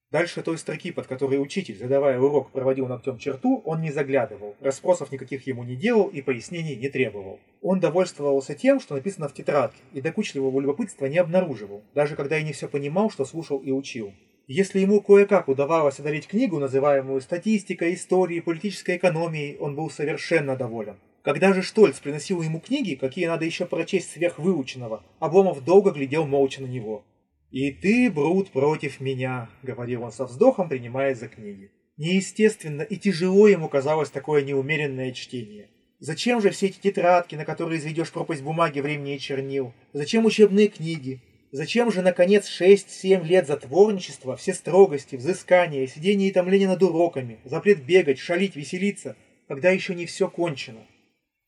Пример записи на микрофон (всенаправленная диаграмма, говорящий в 1 м)
Несмотря на то, что запись проводилась в акустически подготовленном помещении, на ней появилась ощутимая дополнительная реверберация, при этом он практически не потерял читаемости. Кроме того, совершенно незаметно, что говорящий перемещался во время записи, так что со своей задачей в данном случае микрофон справился.